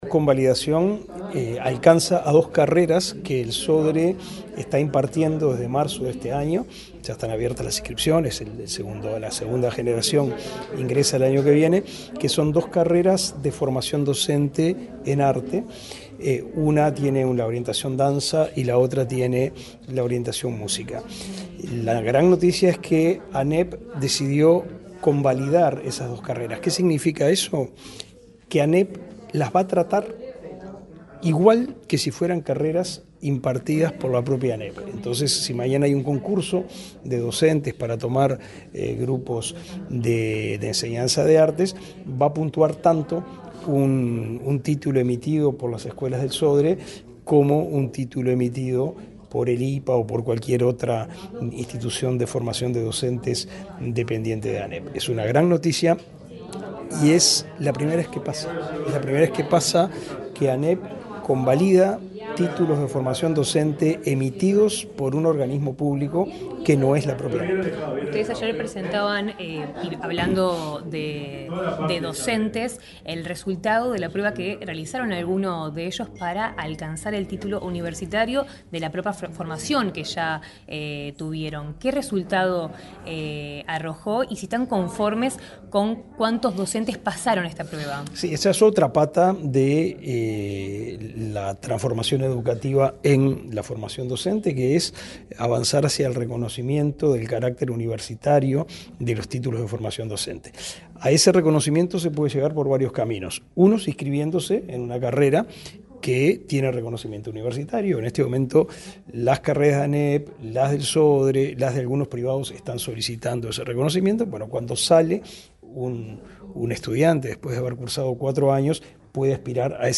Declaraciones del ministro de Educación y Cultura, Pablo da Silveira
Declaraciones del ministro de Educación y Cultura, Pablo da Silveira 04/09/2024 Compartir Facebook X Copiar enlace WhatsApp LinkedIn El titular del Ministerio de Educación y Cultura, Pablo da Silveira, dialogó con la prensa, luego de participar en una conferencia de prensa en la sede de la referida cartera, para informar acerca de la convalidación de títulos de Formación Docente en Artes y la inauguración del edificio de las Escuelas del Sodre.